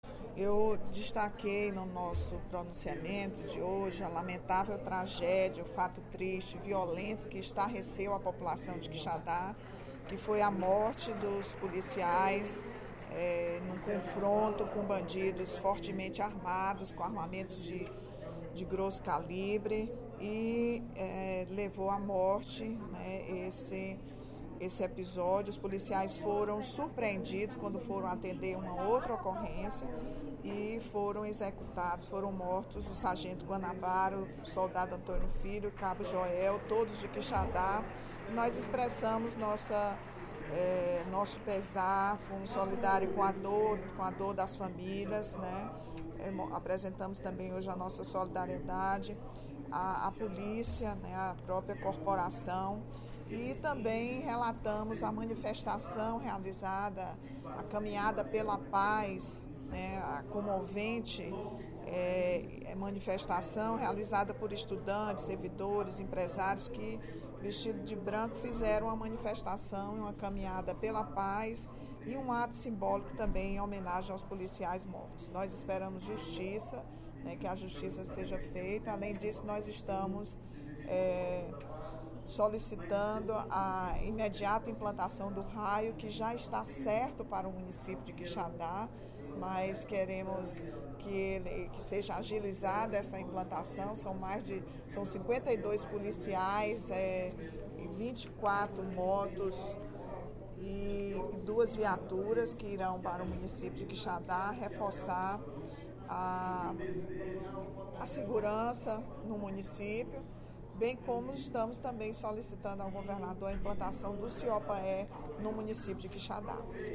A deputada Rachel Marques (PT) lamentou, nesta terça-feira (05/07), durante o primeiro expediente da sessão plenária, a morte de três policiais na última sexta-feira (01/07), no município de Quixadá.
Em aparte, o líder do Governo, deputado Evandro Leitão (PDT), e o vice-líder do Governo, Júlio César Filho (PMB) demonstraram solidariedade ao caso.